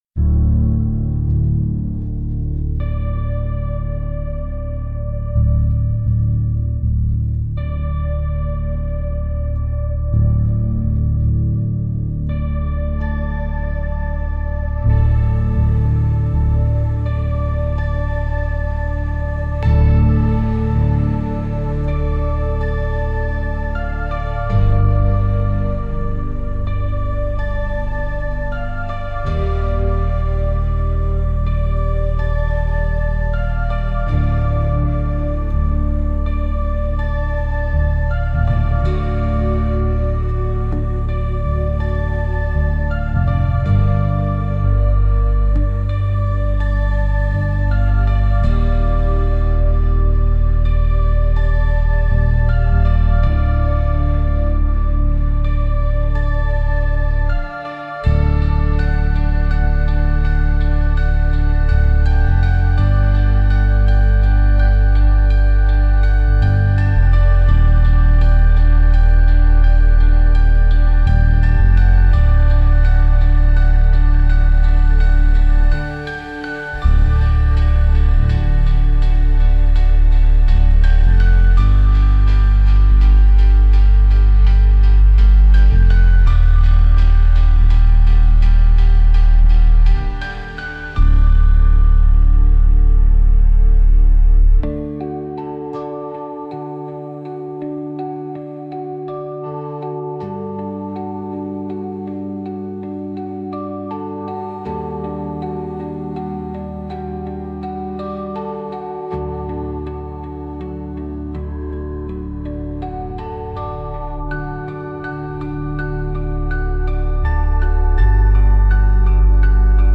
Instrumental - Veil of Waking Dreams - 4. mins